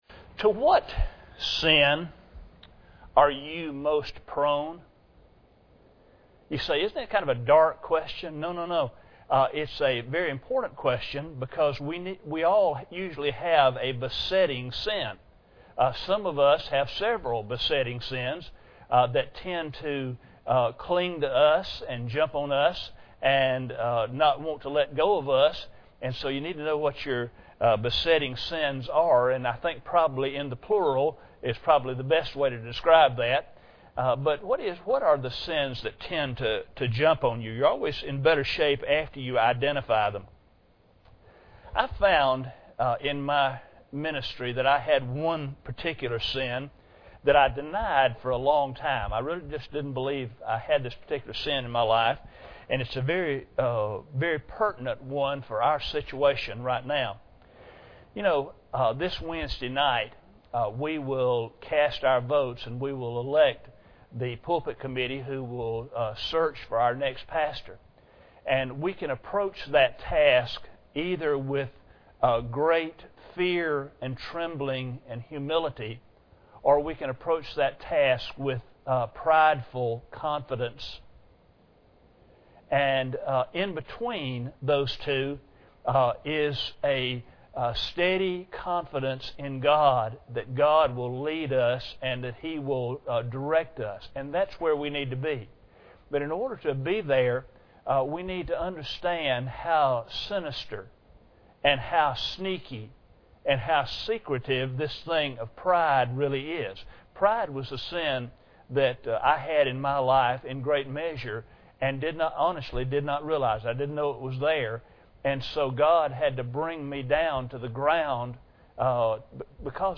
Proverbs 13:10 Service Type: Sunday Evening Bible Text